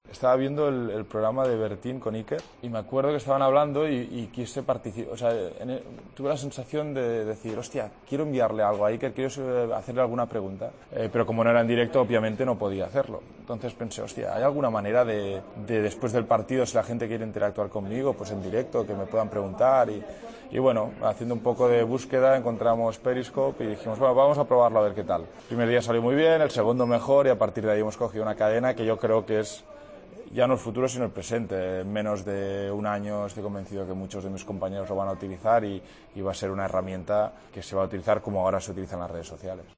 El defensa del Barcelona, asiduo a Periscope, ha explicado en una entrevista sus inicios con esta aplicación y cómo surgió su interés por ella.